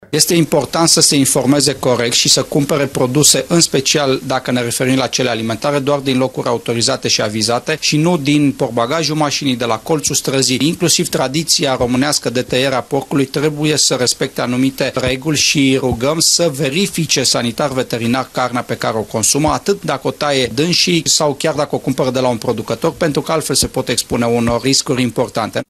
Comisarul șef al CRPC Regiunea Centru, Sorin Susanu, spune că trebuie să fim foarte atenți unde ne facem cumpărăturile, mai ales cele alimentare: